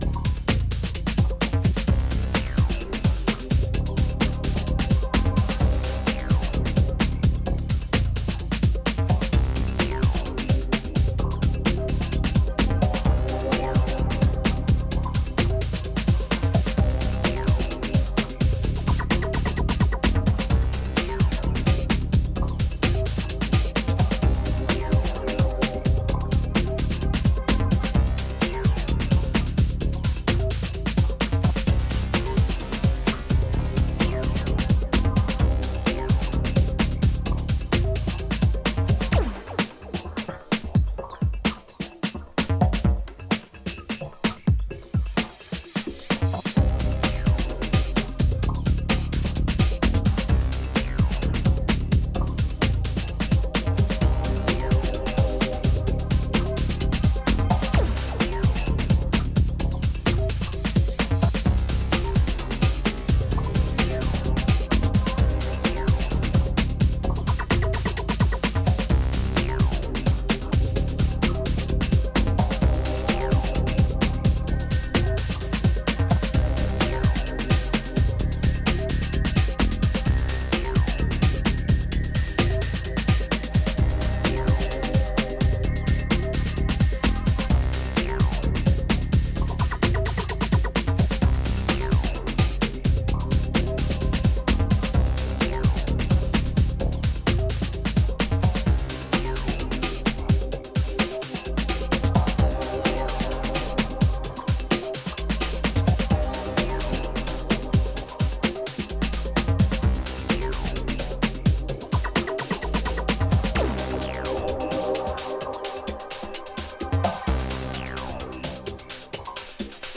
分厚いベースとサイファイなタッチであらゆる帯域からフロアを揺らすアシッド・エレクトロ